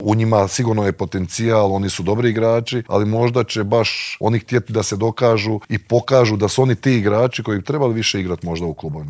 Nakon svjetskog srebra svi se nadaju ponovnom uzletu na smotri najboljih rukometaša starog kontinenta, a o očekivanjima, željama, formi te o tome zašto se u sportu nema strpljenja s trenerima i izbornicima te kako su se nekada osvajale svjetske i olimpijske i klupske titule u Intervjuu Media servisa razgovarali smo bivšim reprezentativcem, legendarnim Božidarem Jovićem.